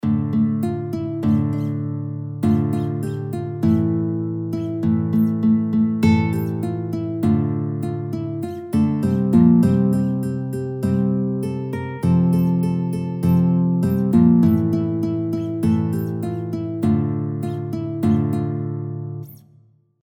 Хрестоматійна повстанська пісня.